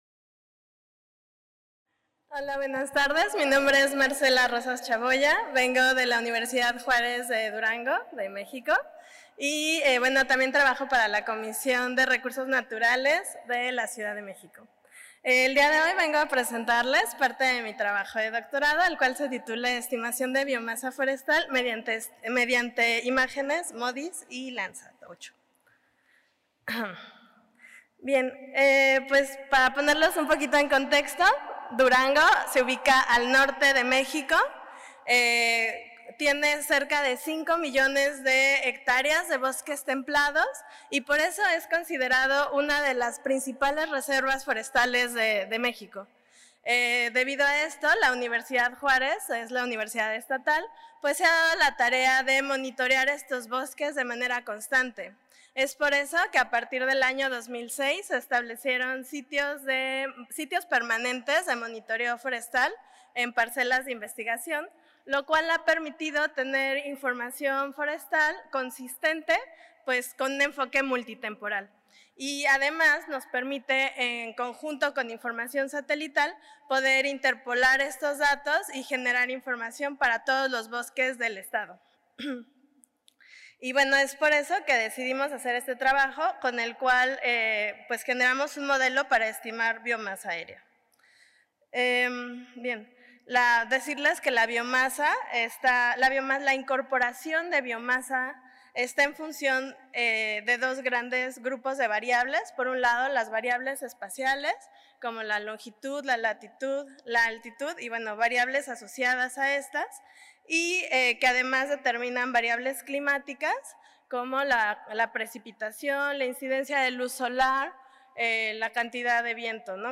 En aquesta ponència s'explica com s'han utilitzat les imatges satèl·lit i el programa QGIS per treure dades útils per la gestió de la massa forestal de la zona de l'Estat de Durango a Mèxic